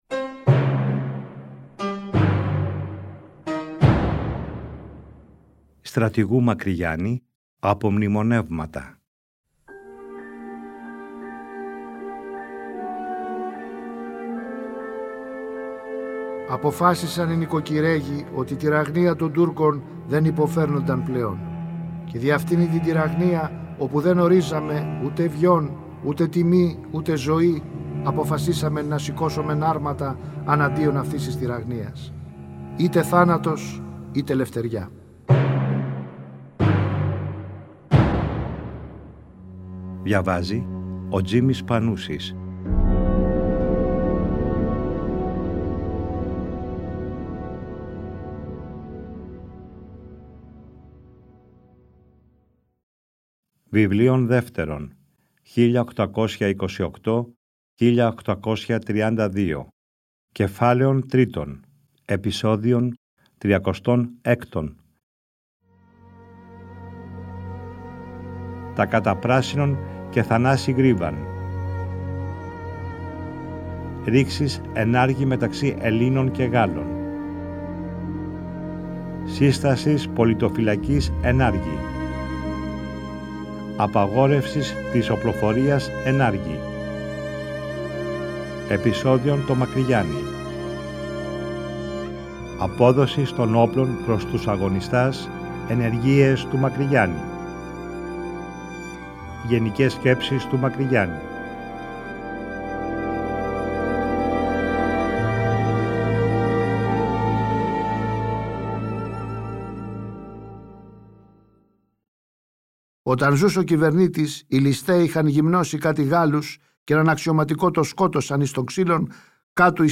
Τον Ιούνιο του 2012 το Τρίτο Πρόγραμμα παρουσίασε για πρώτη φορά μια σειρά 60 ημίωρων επεισοδίων, με τον Τζίμη Πανούση να διαβάζει τον γραπτό λόγο του Μακρυγιάννη, όπως ο ίδιος ο Στρατηγός τον αποτύπωσε στα “Απομνημονεύματα” του. Το ERT εcho σε συνεργασία με το Τρίτο Πρόγραμμα αποκατέστησαν ψηφιακά τα αρχεία.